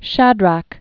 (shădrăk)